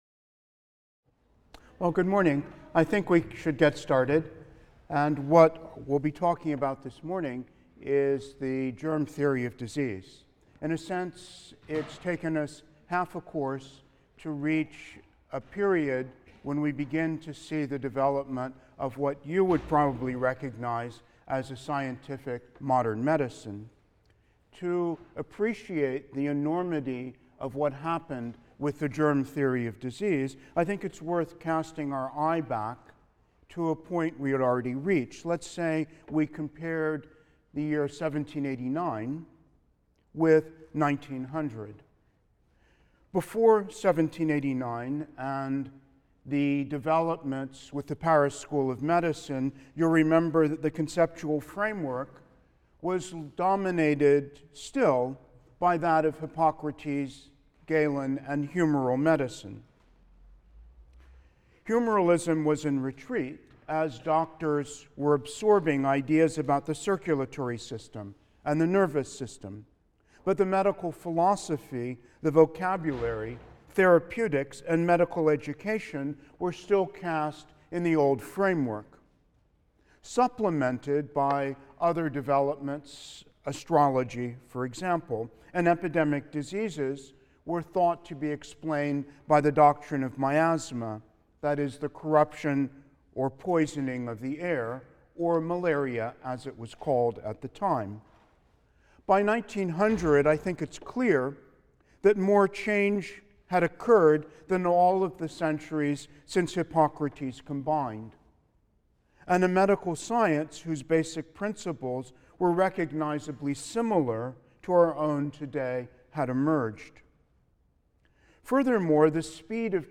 HIST 234 - Lecture 14 - The Germ Theory of Disease | Open Yale Courses